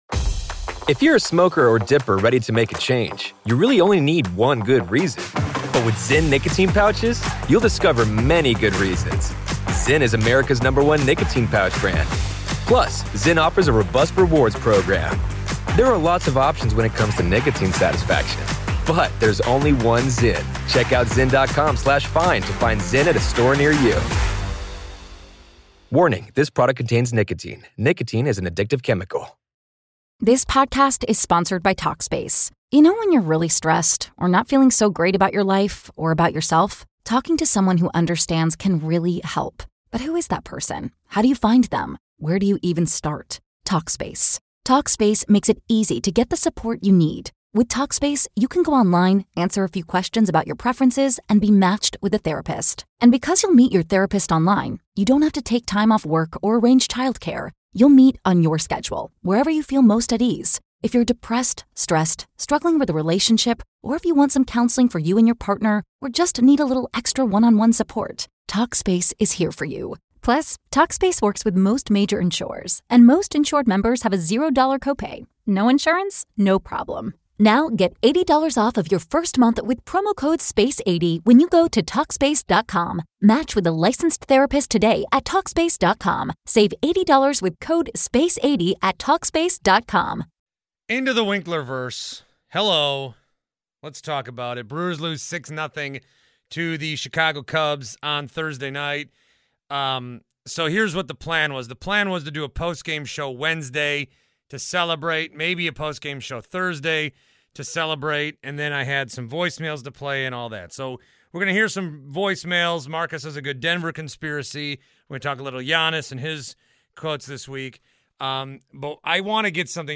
Plus, voicemails, including reaction to the continued Giannis slander driven by the good folks at ESPN Hosted by Simplecast, an AdsWizz company.